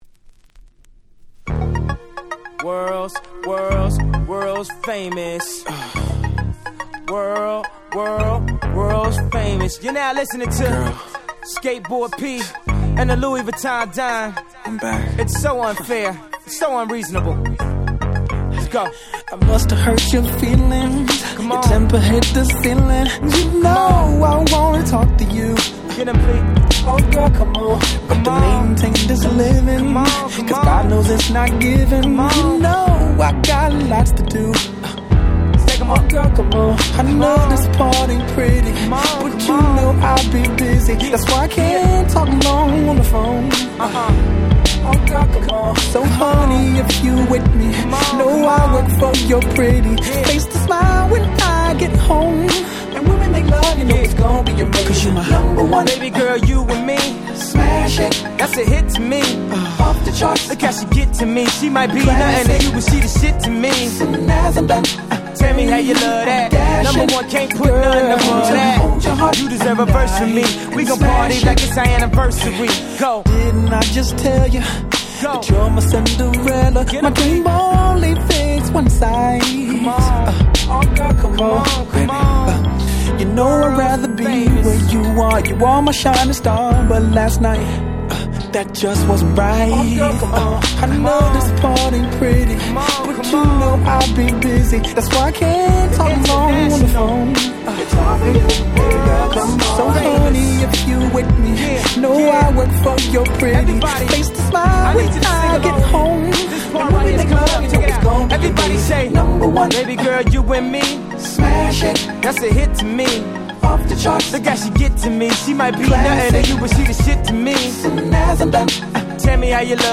06’ Smash Hit R&B !!